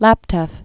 (lăptĕf, -tĕv, läptyĭf)